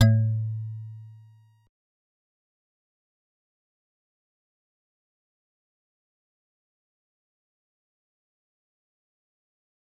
G_Musicbox-A2-pp.wav